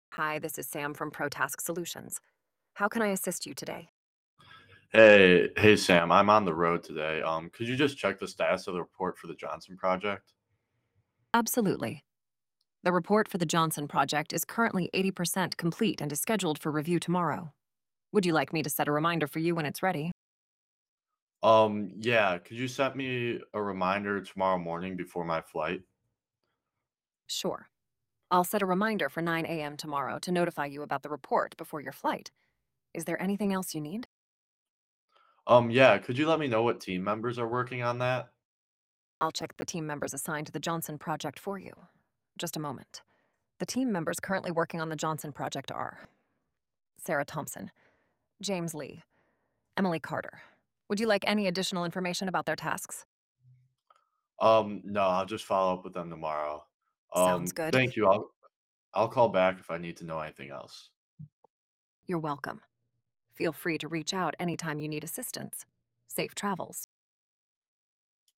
From only $149.99 Monthly 14 day free trial VoicePro the ultimate voice assistant app Seamless information retrieval & hands-free task management Listen to demo conversation now TRY NOW Start Free Trial
Voicepro AI.wav